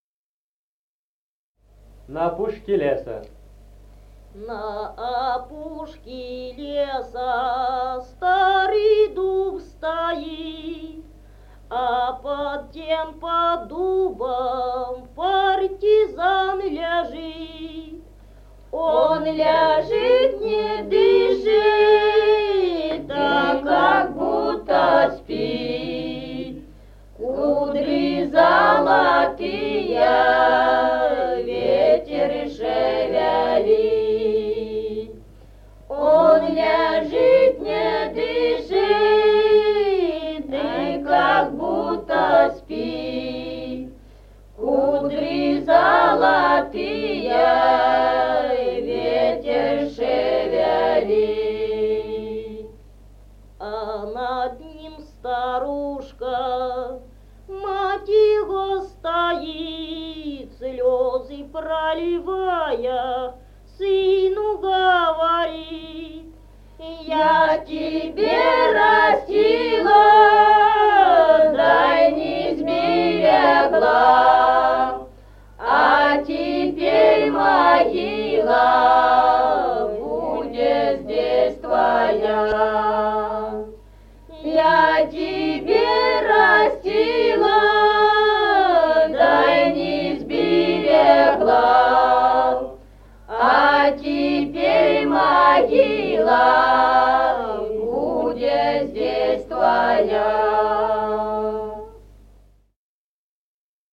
Музыкальный фольклор села Мишковка «На опушке леса», партизанская.